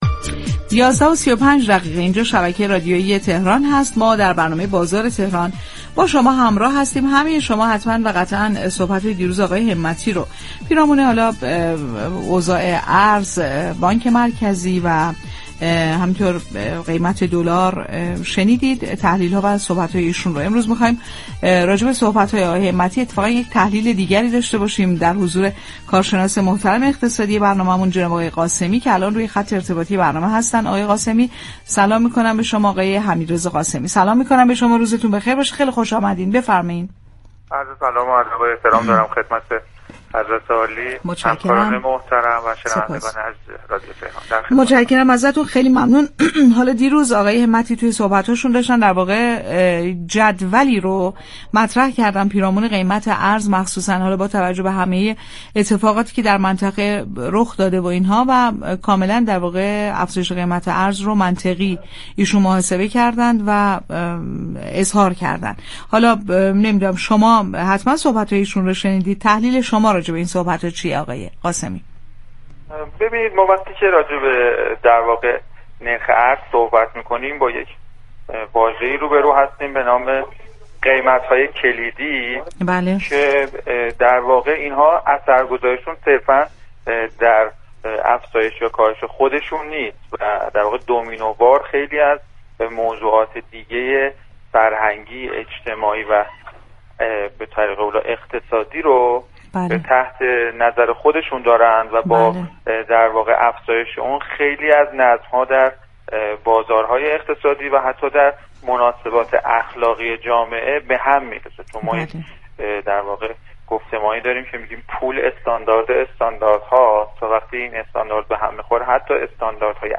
كارشناس اقتصادی در گفت و گو با «بازار تهران»